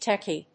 /ˈtɛki(米国英語), ˈteki:(英国英語)/